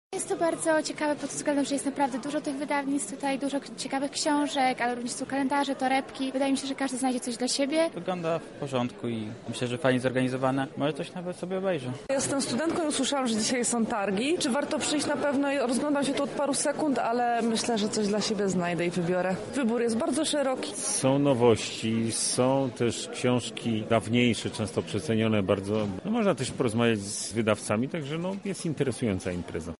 O tym mówią osoby, spotkane między stoiskami: